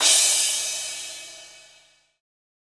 Crash SwaggedOut 1.wav